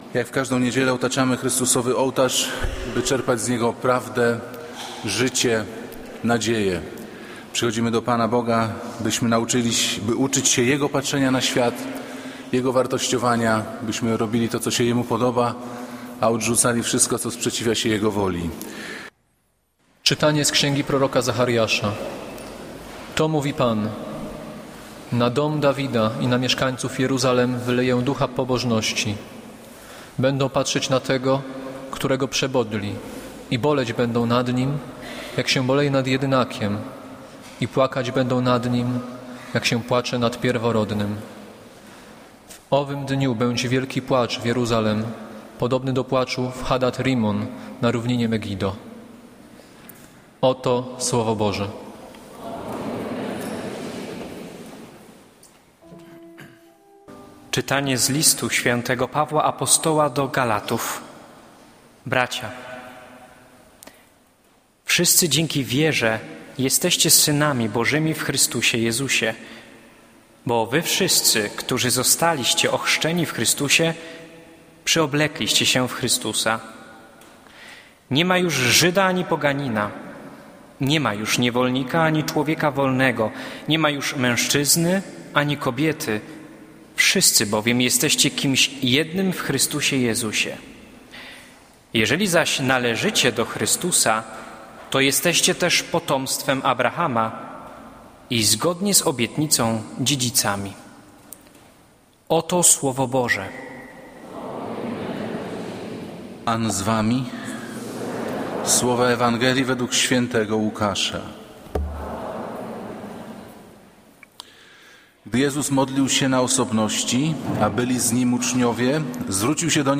Kazanie z 23 czerwca 2013r.
Piotra Pawlukiewicza // niedziela, godzina 15:00, kościół św. Anny w Warszawie « Kazanie z 16 czerwca 2013r.